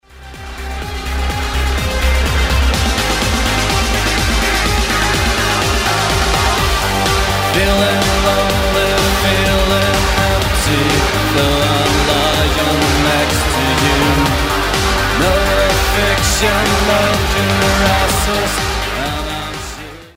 Electro-Pop